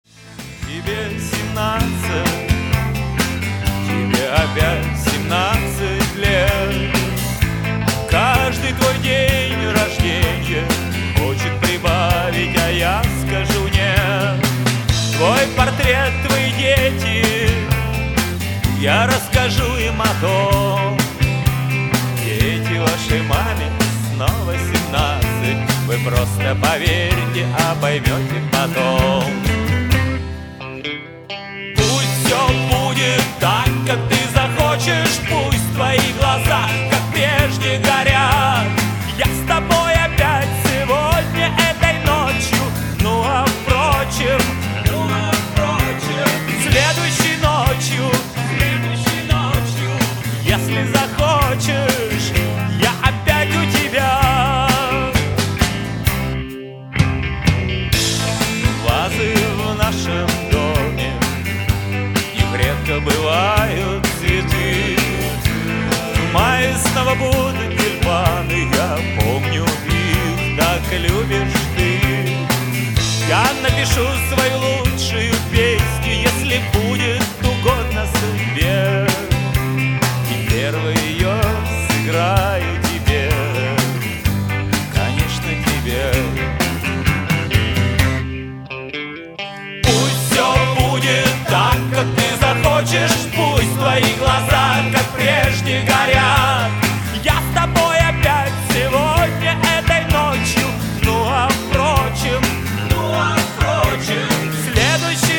• Качество: 256, Stereo
добрые
blues Rock